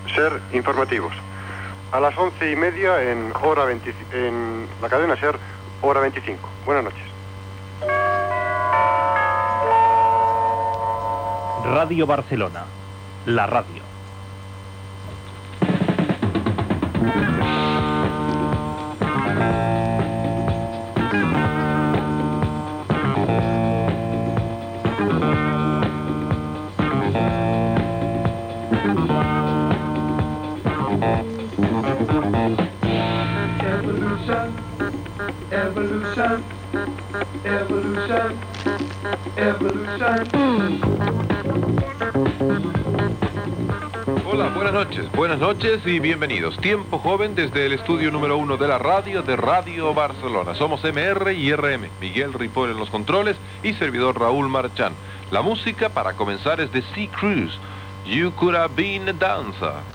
Final SER Informativos, indicatiu (veu de Josep Cuní) i inici del programa.
Musical